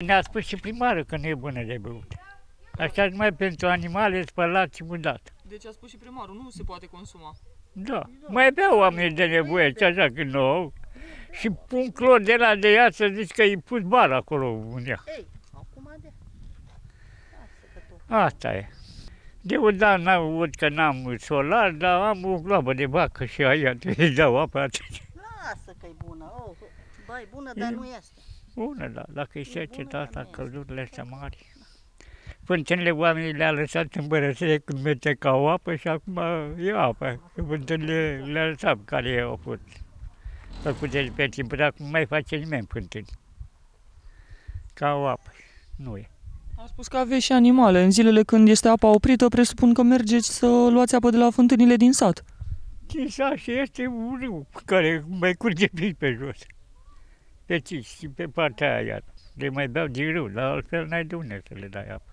Cetățeni, Comuna Bălănești